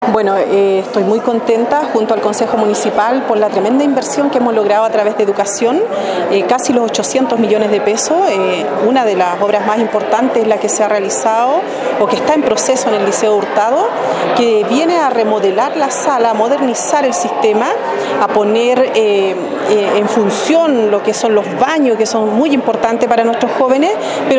La Alcaldesa de la Comuna de Río Hurtado, Carmen Olivares de La Rivera resaltó que
2.-Carmen-Olivares-de-La-Rivera-Alcaldesa-Rio-Hurtado_.mp3